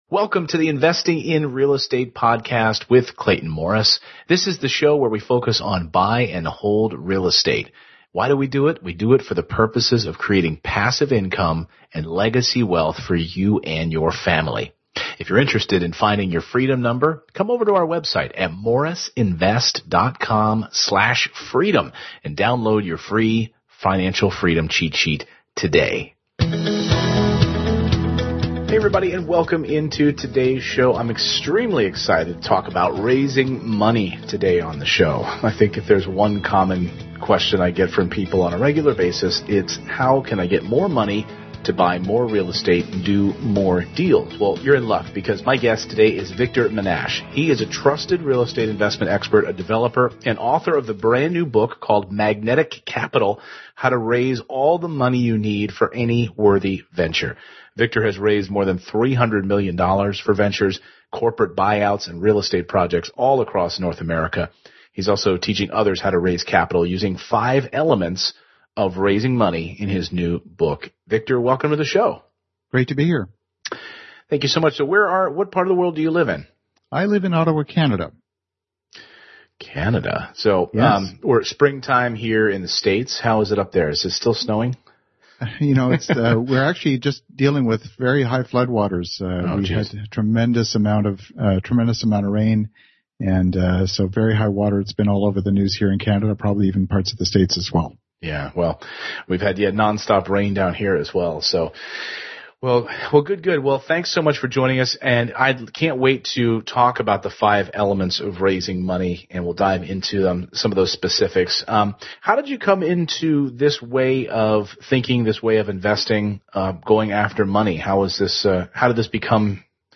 Speaking with host Clayton Morris, well known as the host of Fox and Friends on Fox News. We're talking about raising capital.
Clayton is a masterful interview host.